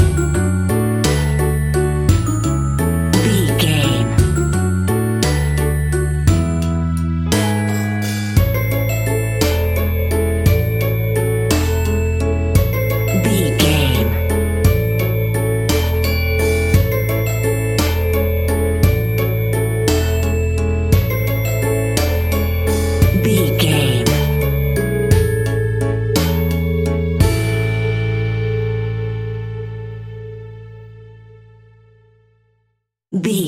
Ionian/Major
childrens music
instrumentals
childlike
cute
happy
kids piano